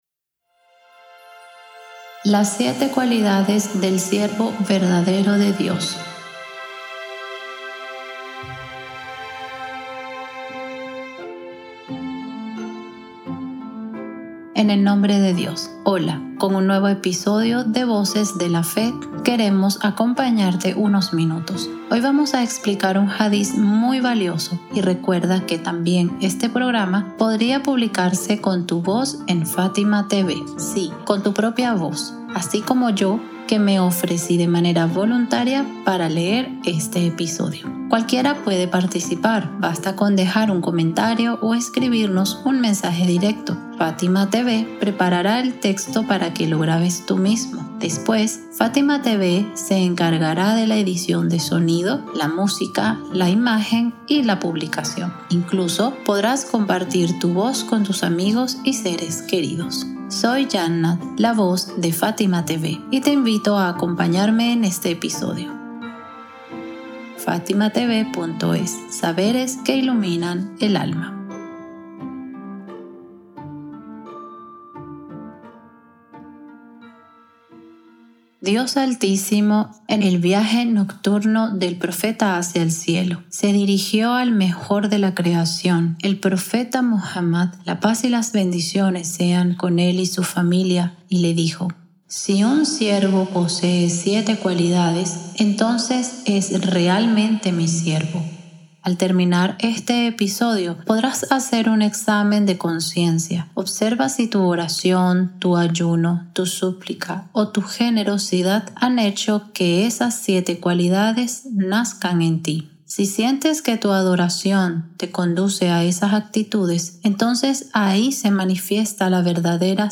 ✨ 🎙 Locutora